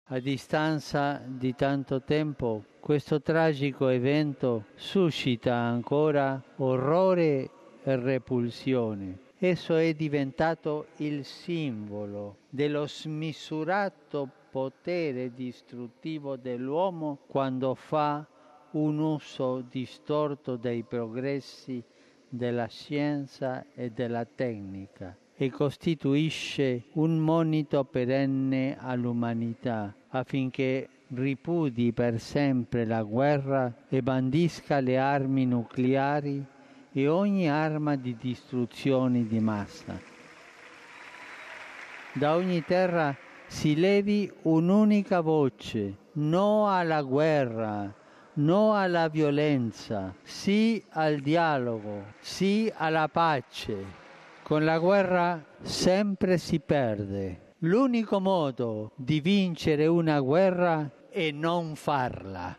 All’Angelus Papa Francesco ha ricordato anche che 70 anni fa, il 6 e il 9 agosto del 1945, avvennero i "tremendi bombardamenti atomici" su Hiroshima e Nagasaki.